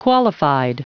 Prononciation du mot qualified en anglais (fichier audio)
Prononciation du mot : qualified